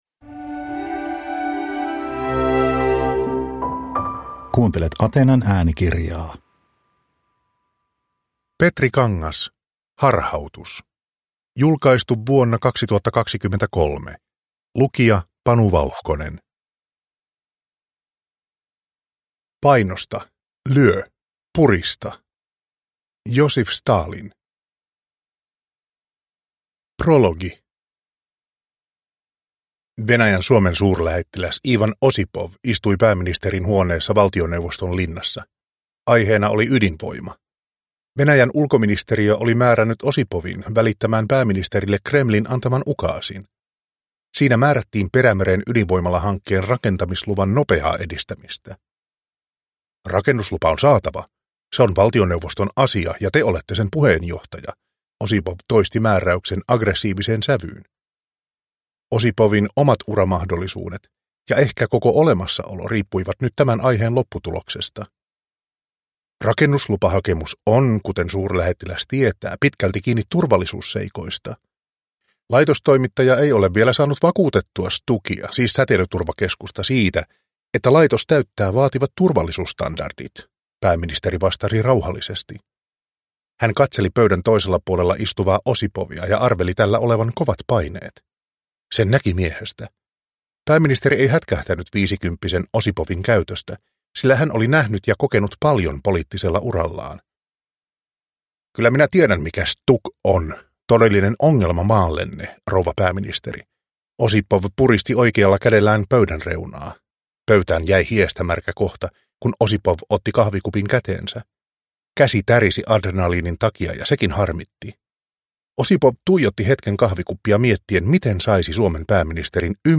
Harhautus – Ljudbok – Laddas ner